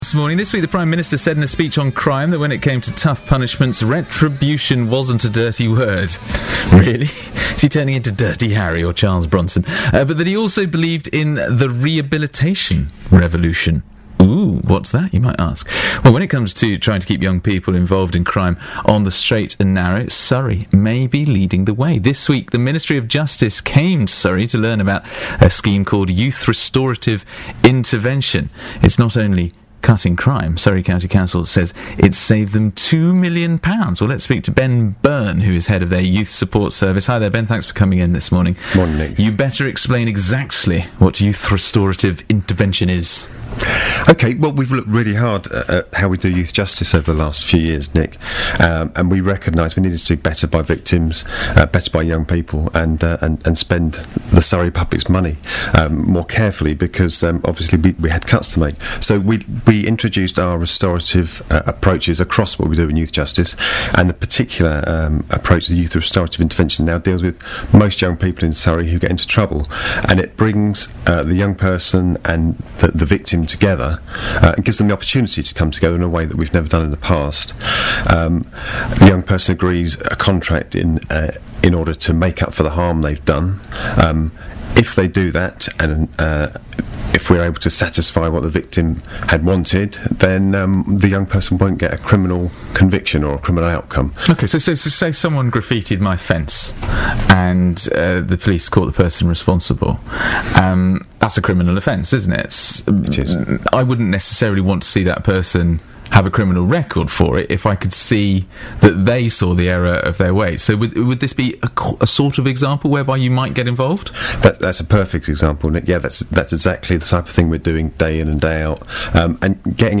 Youth head interviewed by BBC about new approach from council